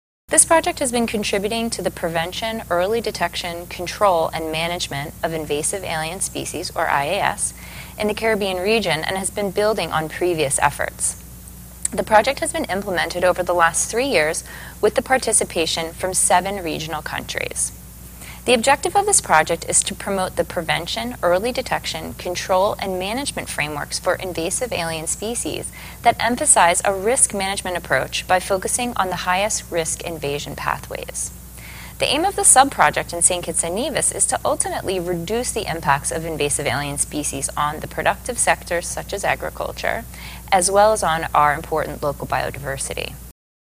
During a panel discussion organized by the St. Kitts Department of Agriculture